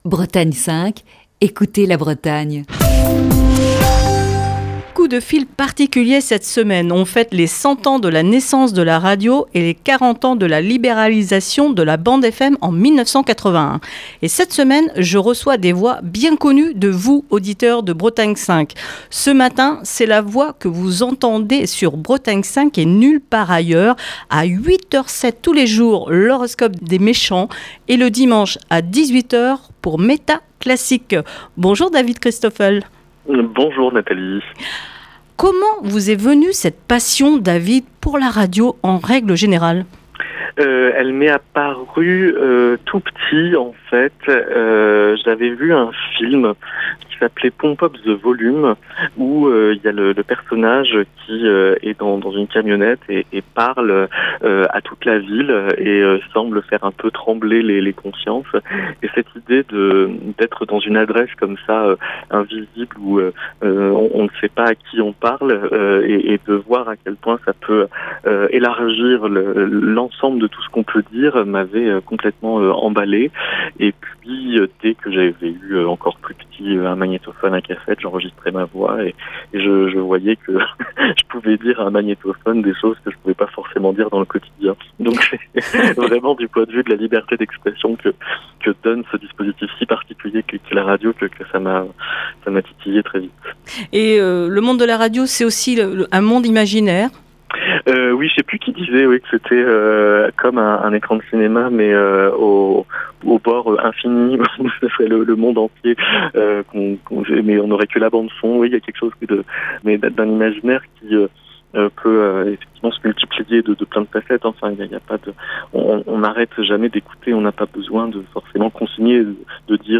Cette semaine, Le coup de fil du matin se met au diapason de la Fête de la Radio, avec les voix des animateurs et producteurs de Bretagne 5.